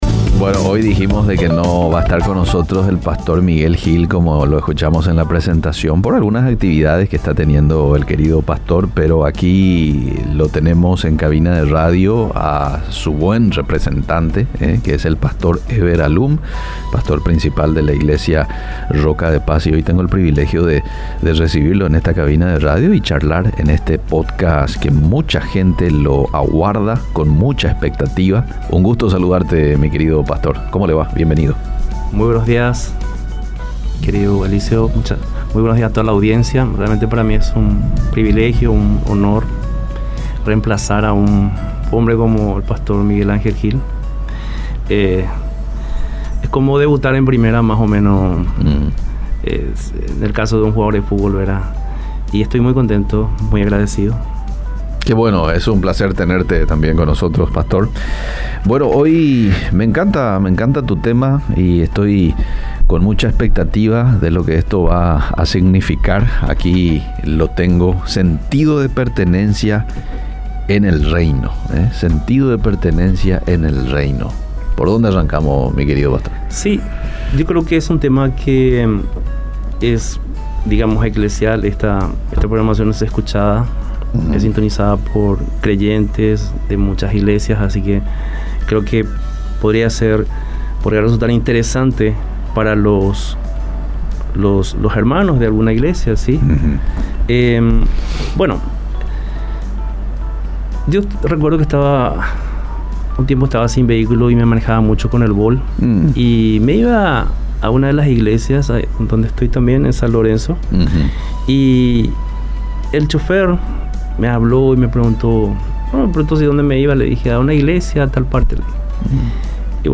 Iglesia La Estación presenta, una conversación franca, sincerca dónde buscamos crecer juntos.